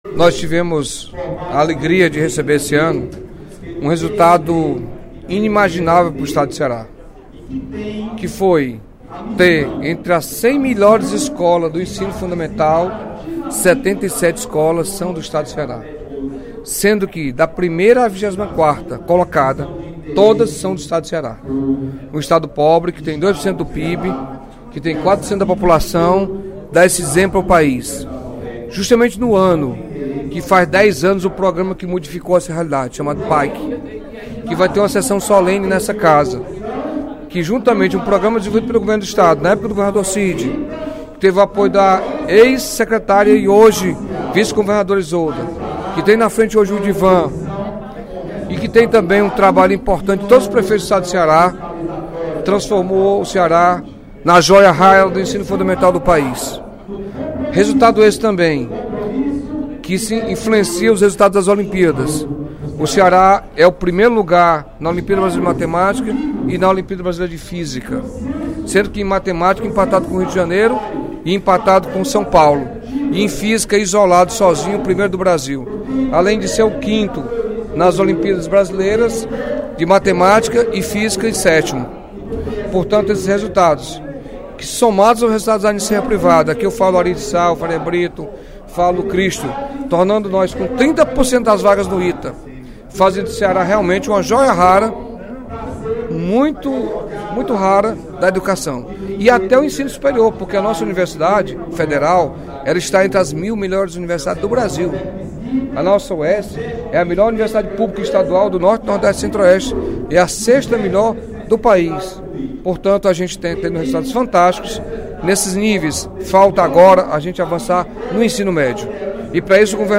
O deputado Carlos Felipe (PCdoB) ressaltou, durante o primeiro expediente da sessão plenária desta quarta-feira (08/02), os avanços na educação do Estado.